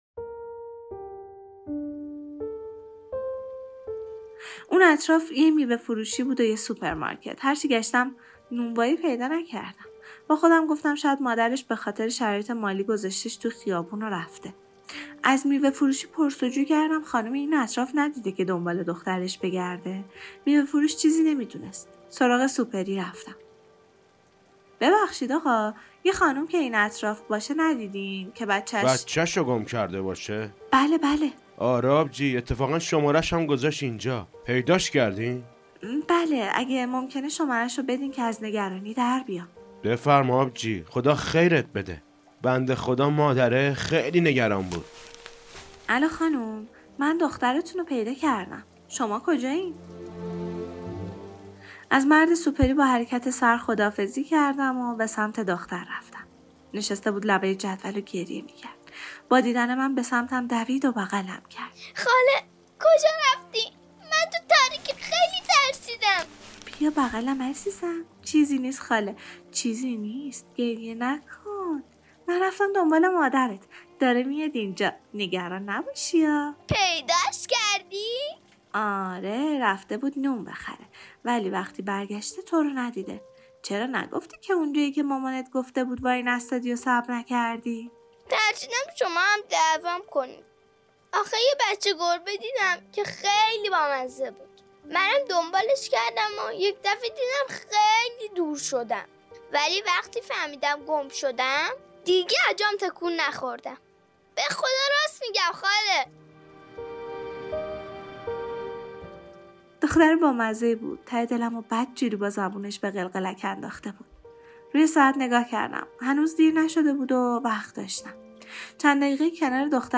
سقط جنین داستان صوتی